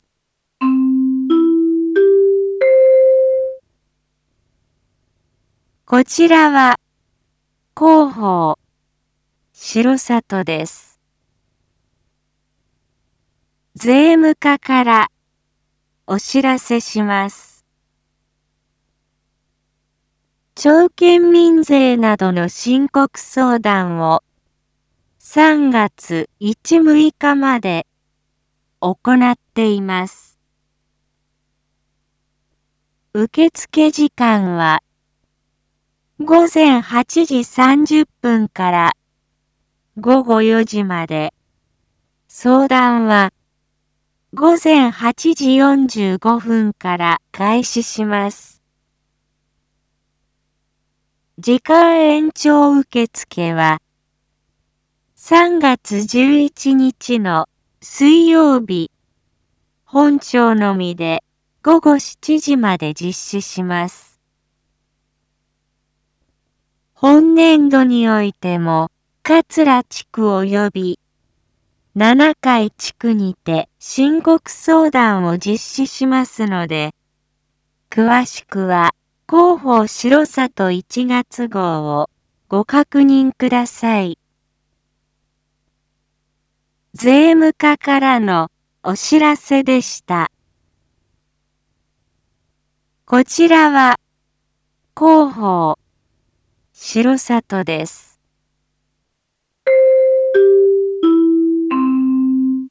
一般放送情報
Back Home 一般放送情報 音声放送 再生 一般放送情報 登録日時：2026-03-09 07:01:44 タイトル：R8申告相談④ インフォメーション：こちらは広報しろさとです。